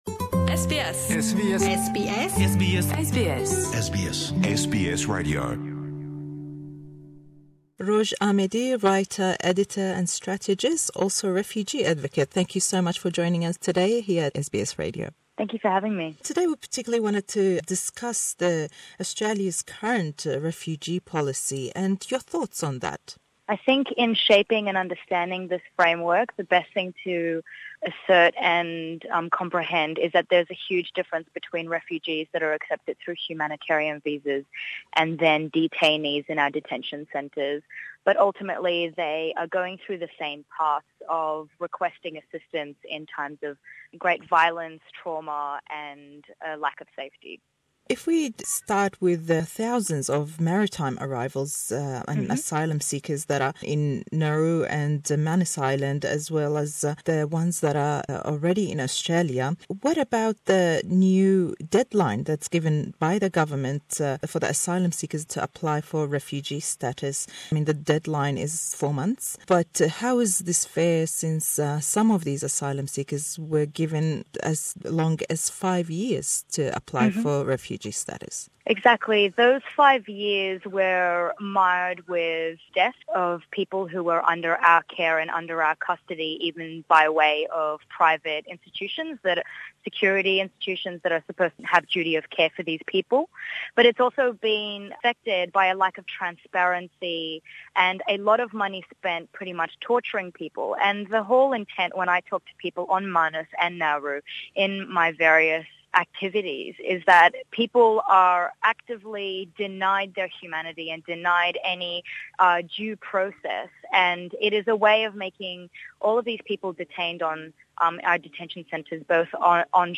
Le em hevppeyvîne da, êma pirsîyarî lêdekeyn derbarey polisî Australya bo penaberan û çend babetî peywest be ew mijare we.